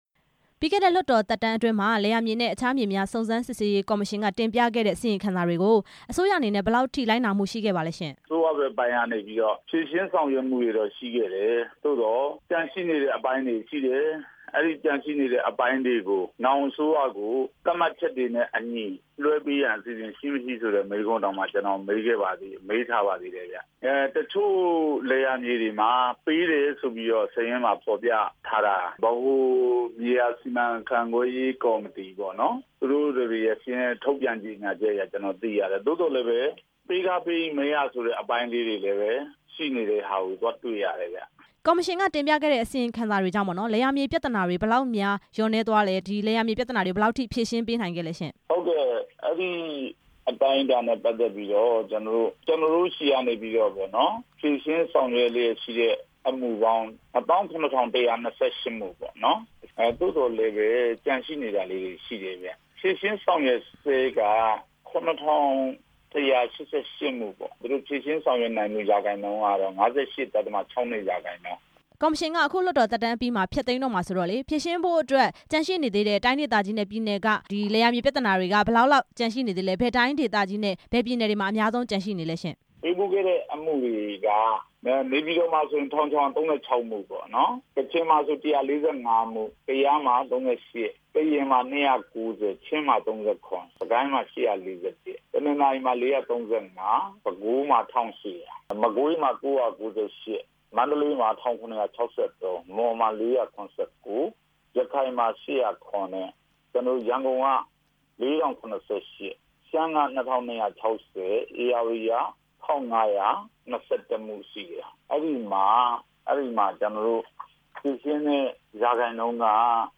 လယ်ယာမြေ ပြဿနာတွေအကြောင်း မေးမြန်းချက်